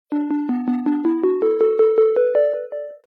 level_end_sfx.mp3